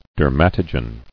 [der·mat·o·gen]